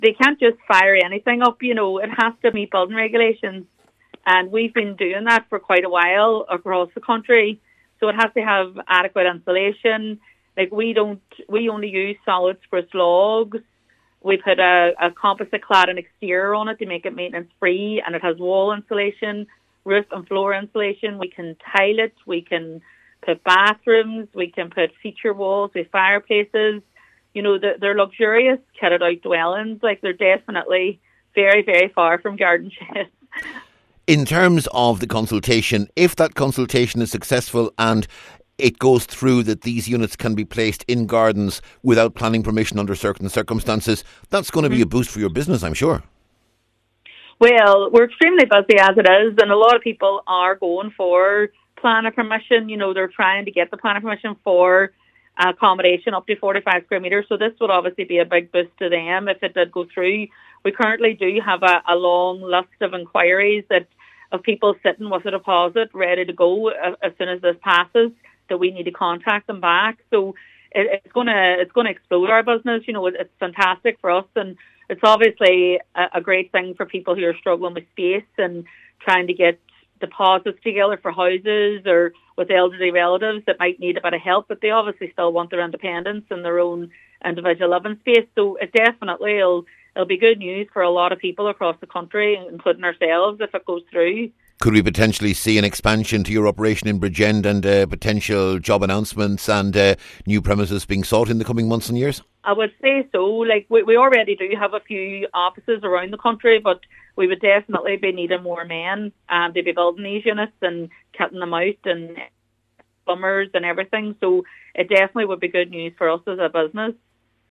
Speaking from the company’s Bridgend base